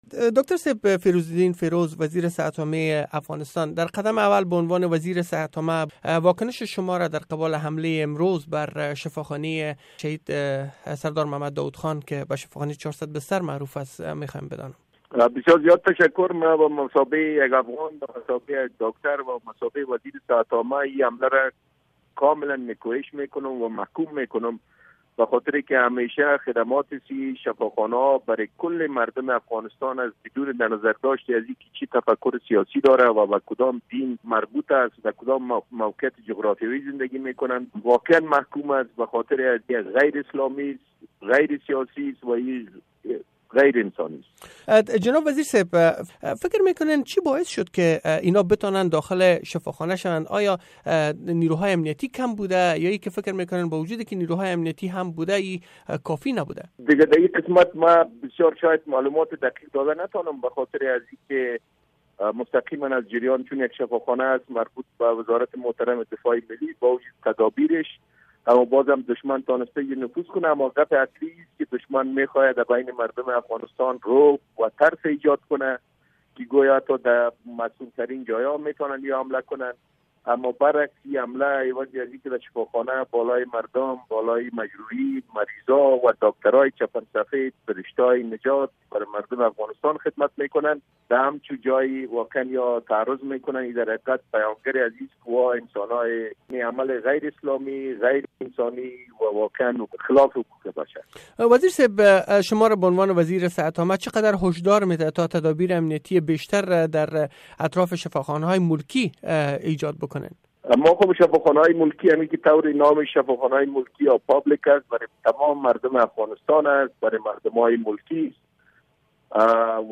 جریان کامل مصاحبه با داکتر فیروزالدین فیروز را ازینجا شنیده می توانید